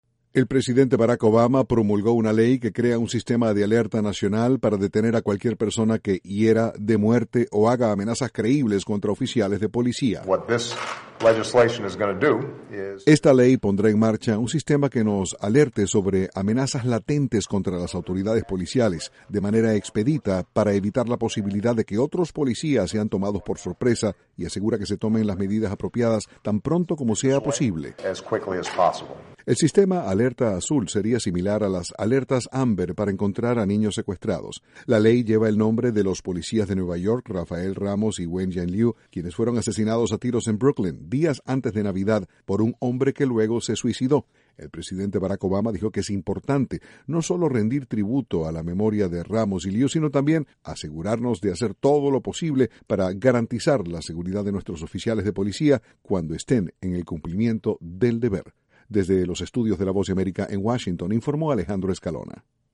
El presidente de Estados Unidos, Barack Obama, promulgó una ley de alerta nacional para prevenir el asesinato de policías. Desde la Voz de América, Washington